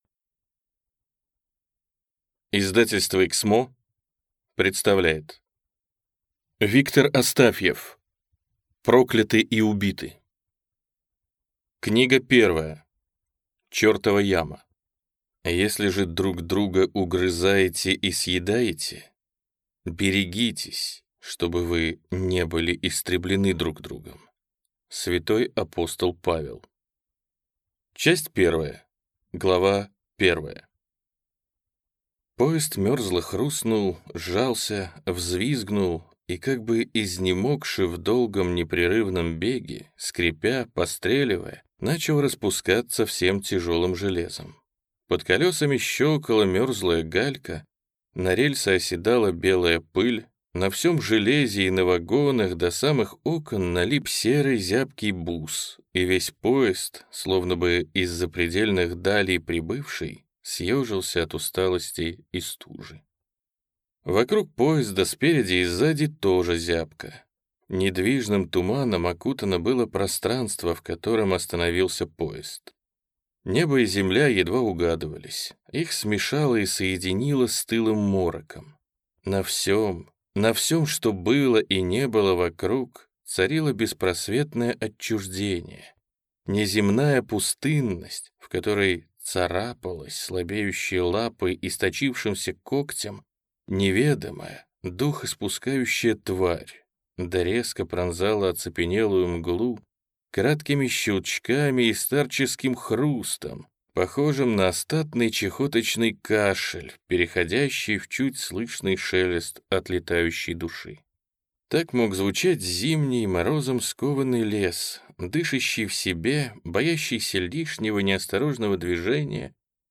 Аудиокнига Прокляты и убиты | Библиотека аудиокниг